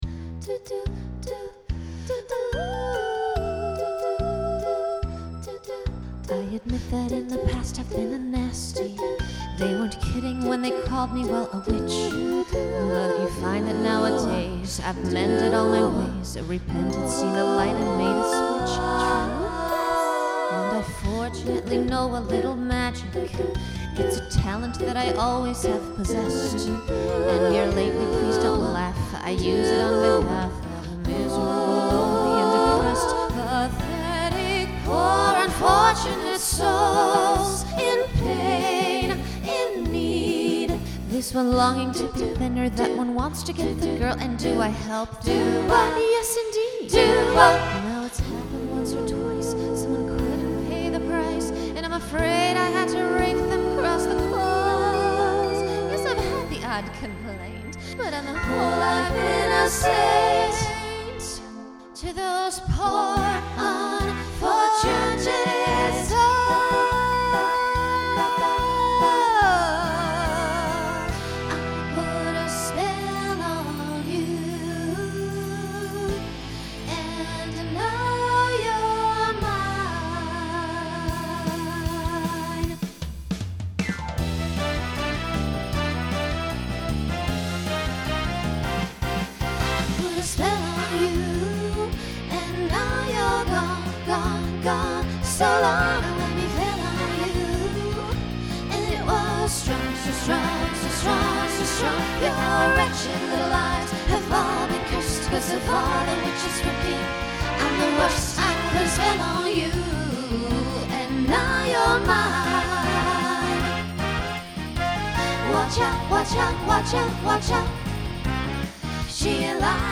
New SATB voicing for 2022.
Genre Broadway/Film
Mid-tempo
Voicing SATB , SSA